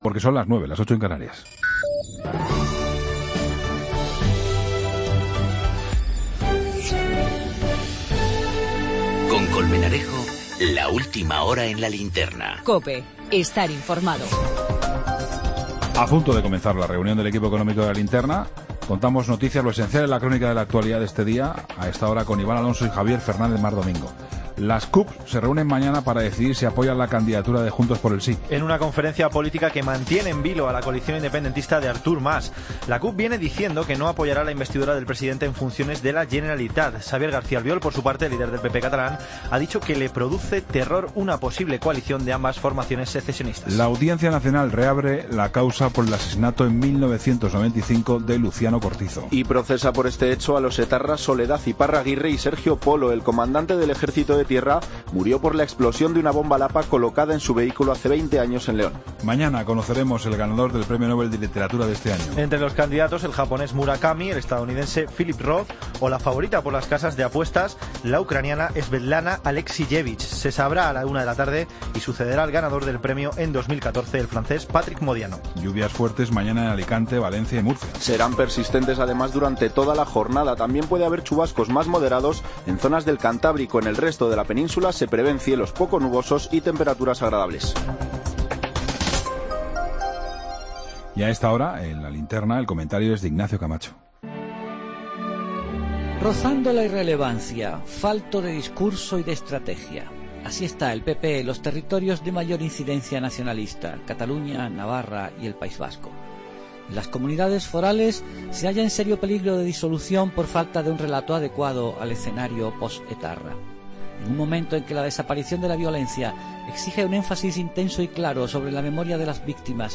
El comentario de Ignacio Camacho.
la tertulia económica
Entrevista